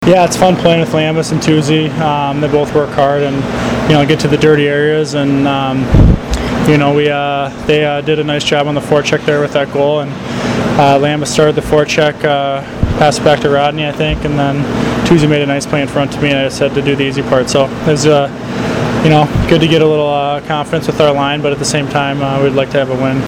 post-game interviews